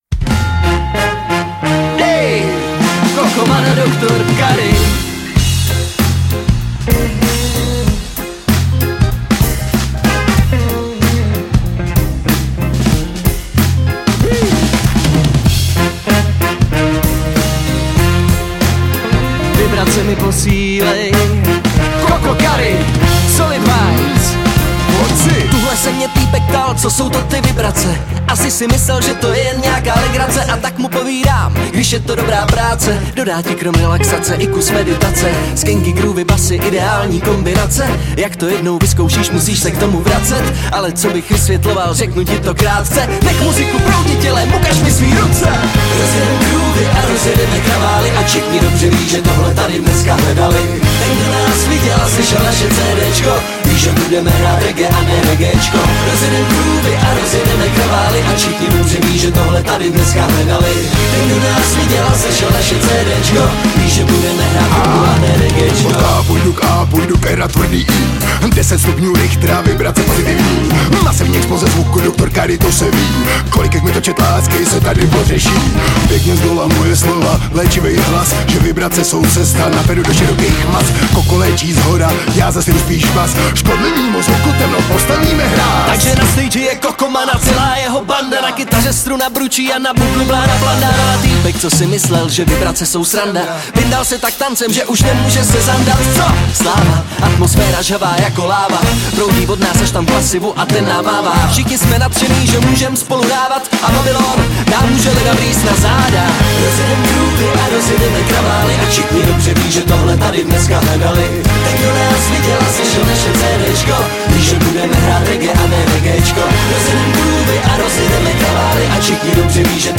Žánr: Ska/Funk/Reggae